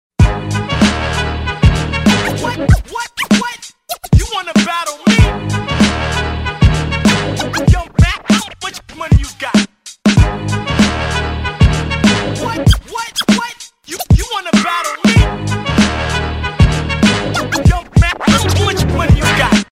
Грозный Рэп